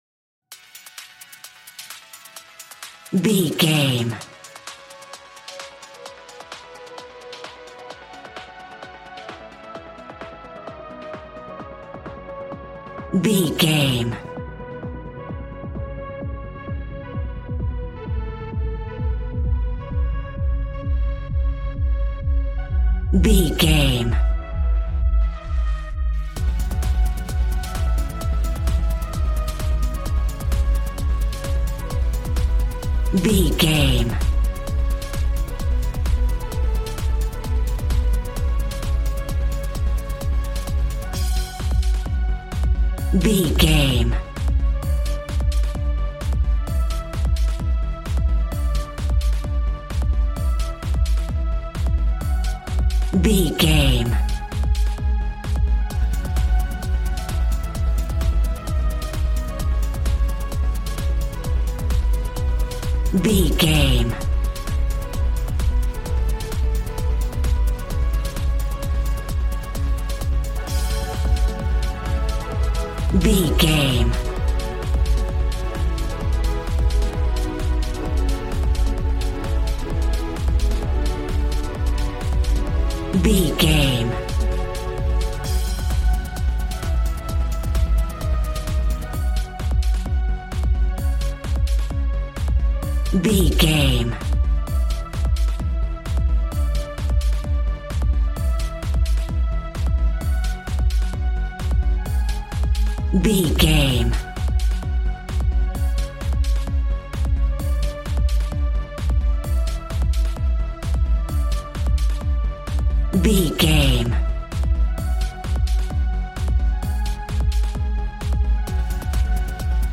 Aeolian/Minor
D♭
Fast
uplifting
lively
groovy
synthesiser
drums